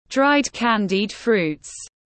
Mứt tiếng anh gọi là dried candied fruits, phiên âm tiếng anh đọc là /draɪd ˈkæn.did fruːt/
Dried candied fruits /draɪd ˈkæn.did fruːt/